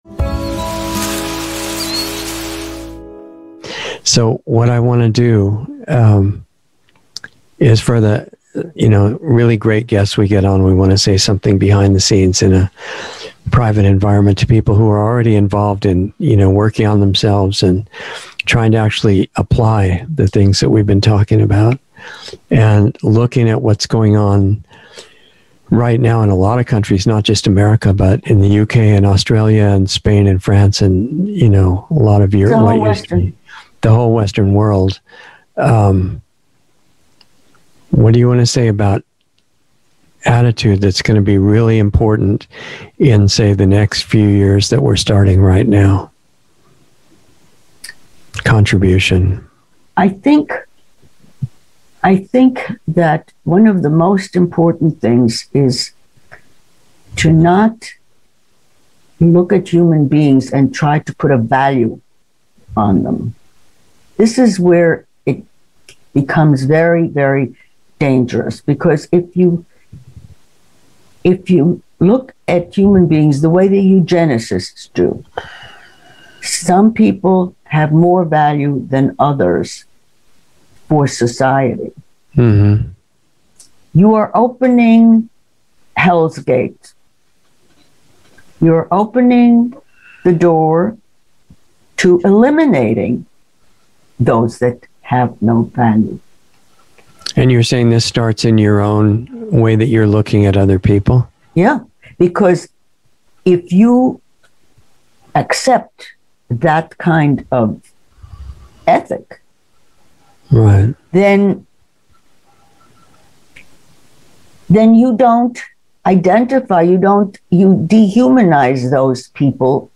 Insider Interview 4/29/21